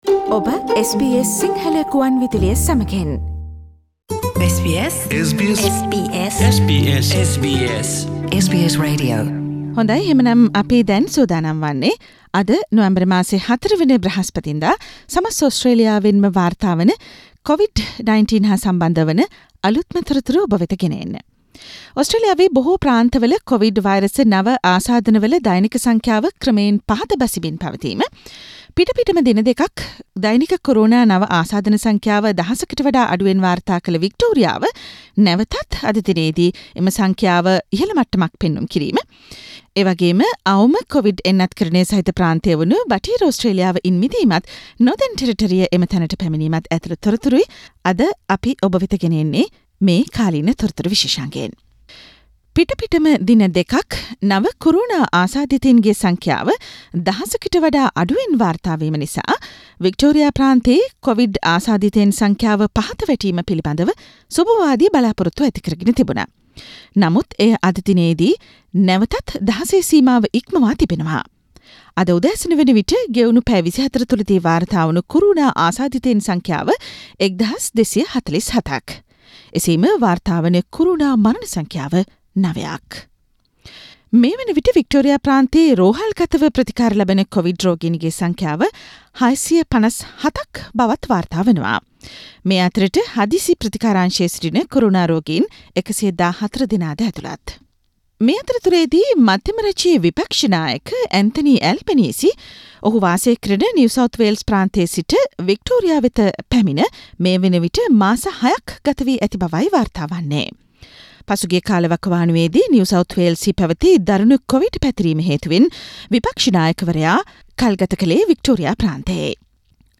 Thursday, November 4, 2021, SBS Sinhala Radio current Affair Feature. This feature contains the latest information on COVID 19 reported from all over Australia.